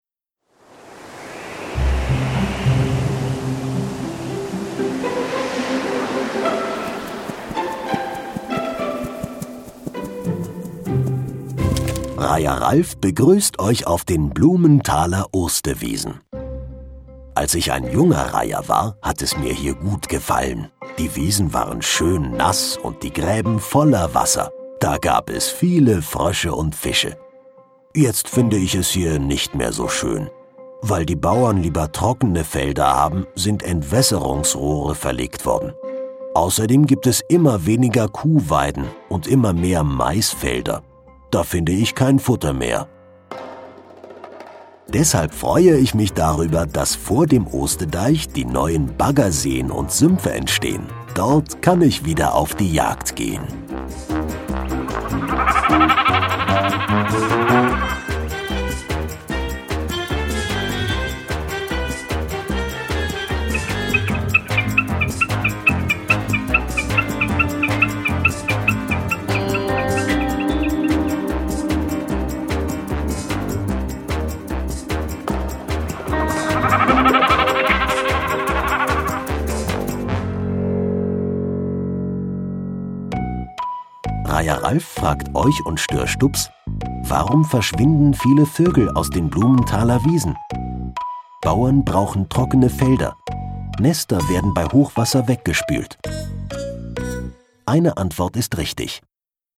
Nedderweg Wiesen - Kinder-Audio-Guide Oste-Natur-Navi